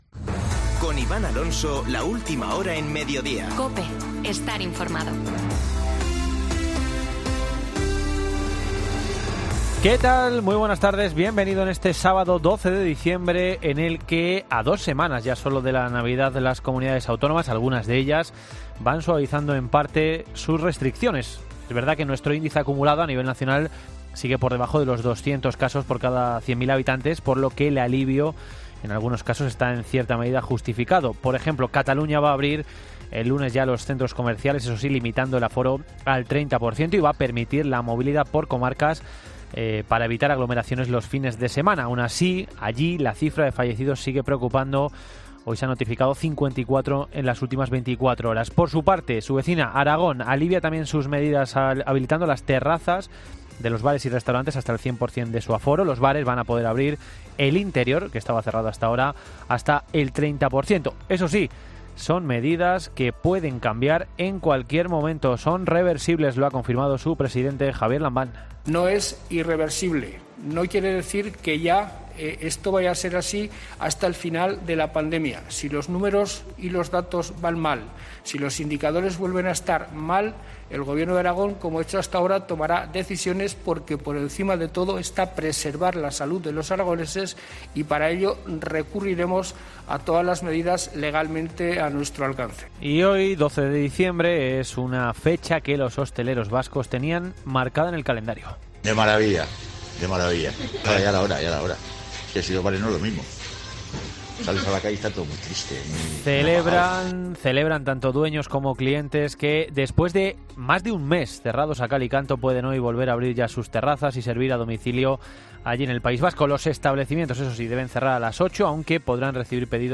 Boletín de noticias de COPE del 12 de diciembre de 2020 a las 14.00 horas